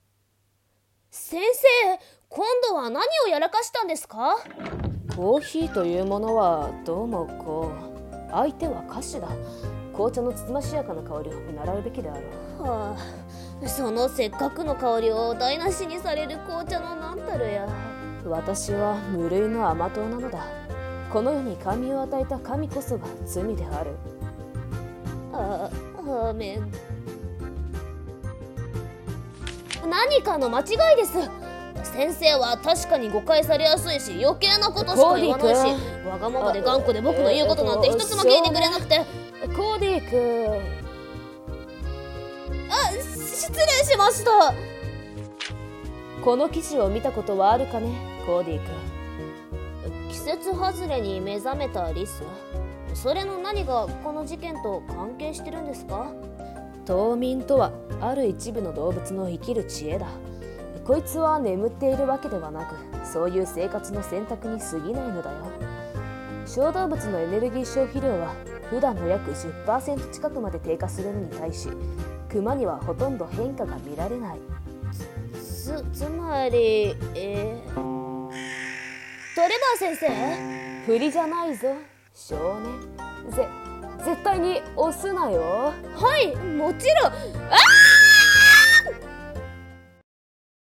【声劇】難題なレーツェル